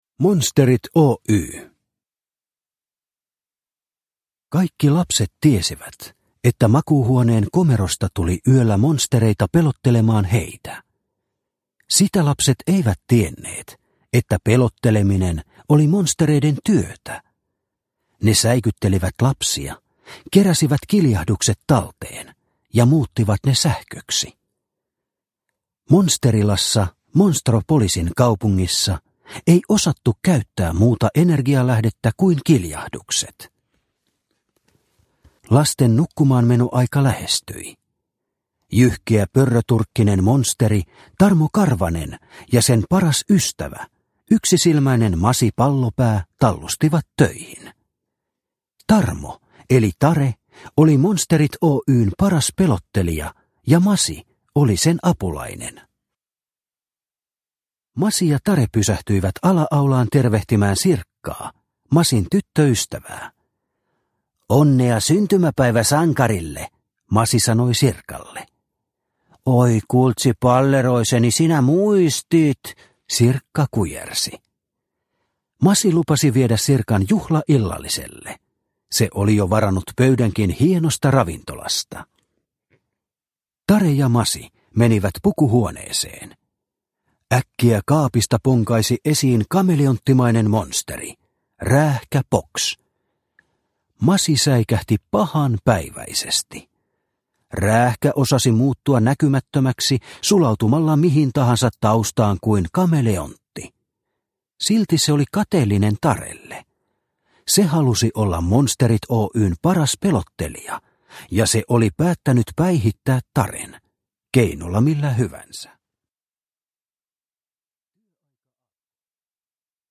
Monsterit Oy – Ljudbok – Laddas ner